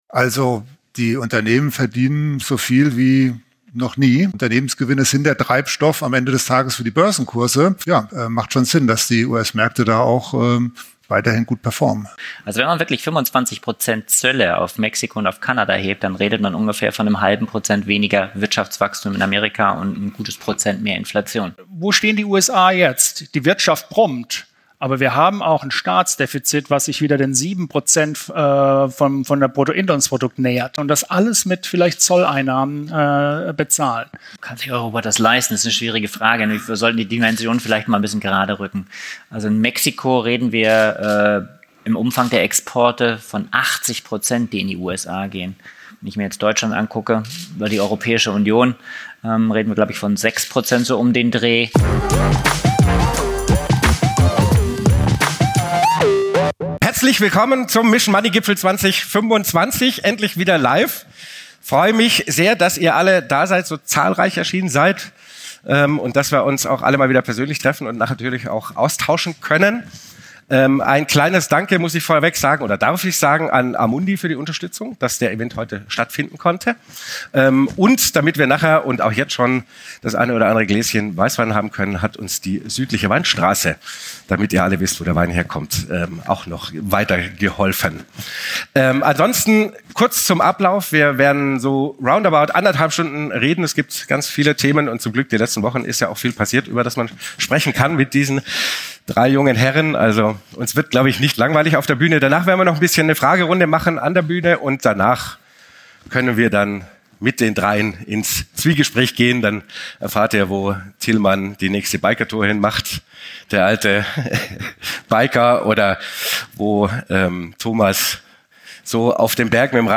Wir befragen für dich jede Woche die besten Finanz- und Wirtschafts-Experten zu aktuellen Themen rund um dein Geld. powered by FOCUS MONEY
Interview